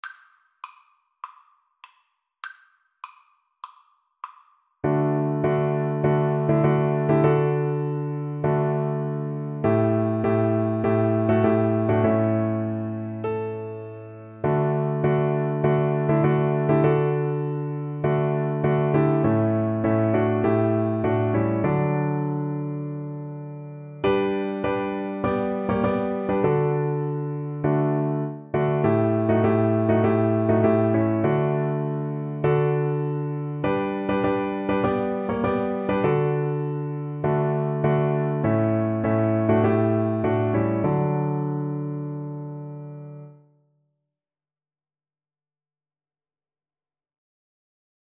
Play (or use space bar on your keyboard) Pause Music Playalong - Piano Accompaniment Playalong Band Accompaniment not yet available reset tempo print settings full screen
4/4 (View more 4/4 Music)
D major (Sounding Pitch) (View more D major Music for Viola )
Traditional (View more Traditional Viola Music)